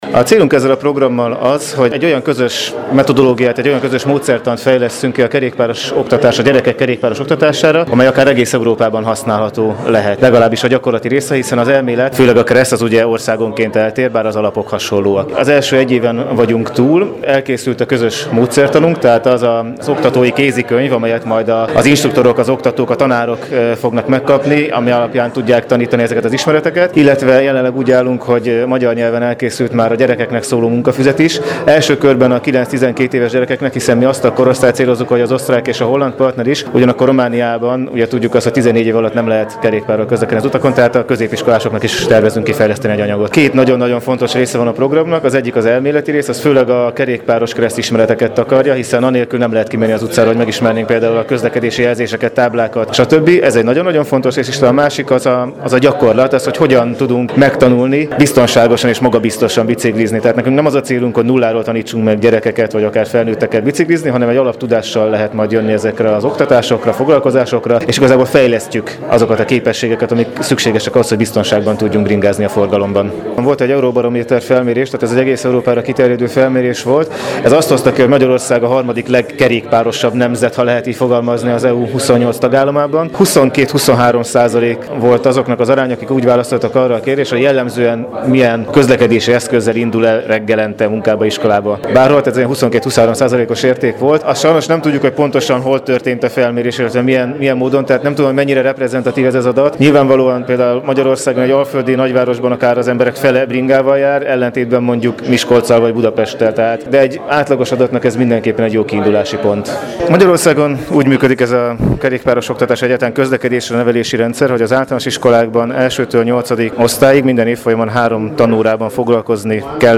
A riportokat